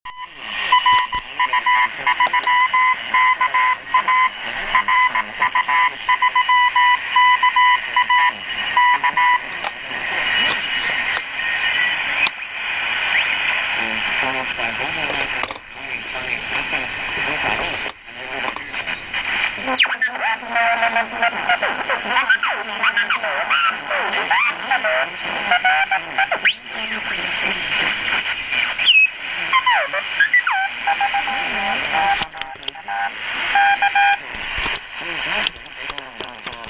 Увы, помехи от радиовещательных станций, всё равно имеются.
Я покрутил, записи прилагаю, можно уменьшить помехи, заодно с чувствительностью...